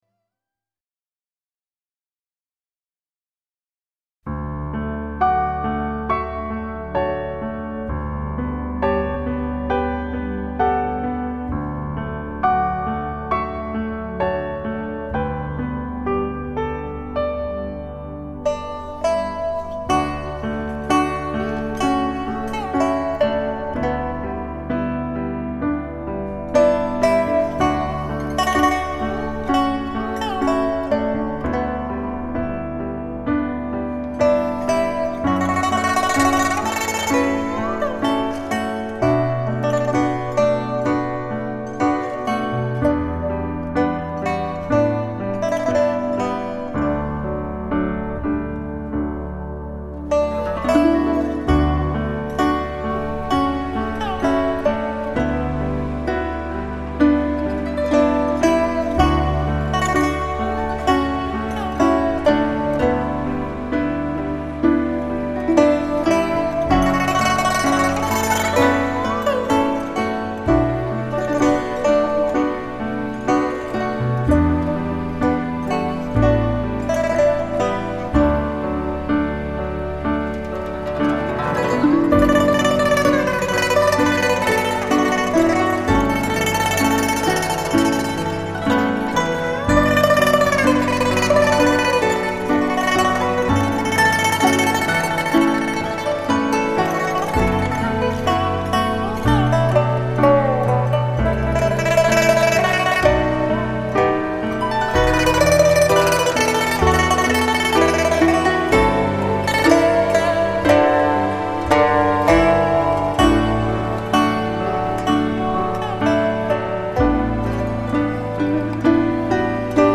新しい時代の訪れを感じさせる、神秘的なエイジアン·サウンドの集大成
新时代的到来，神秘亚洲音乐集大成者。